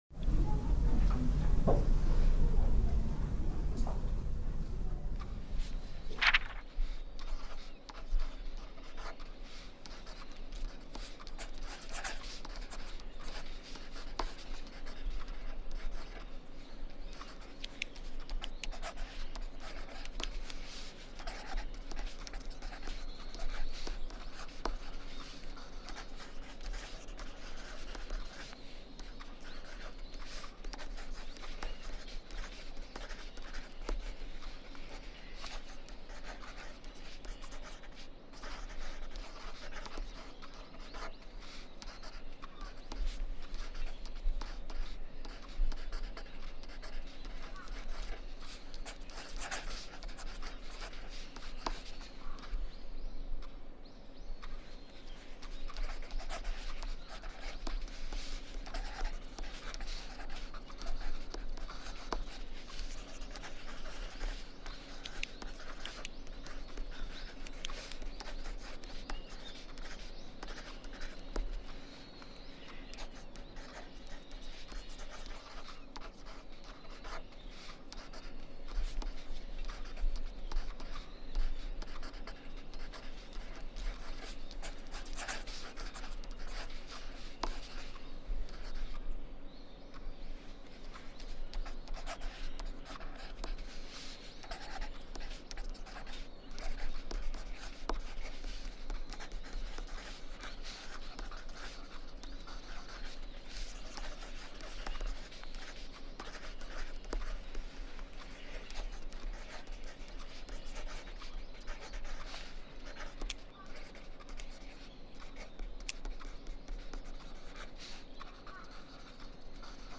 Study Sounds, Background Sounds, Programming Soundscapes, Coding Beats Writing Focus Timer – 60-Minute Session for Test Study May 11 2025 | 01:00:21 1x 00:00 / 01:00:21 Subscribe Share RSS Feed Share Link Embed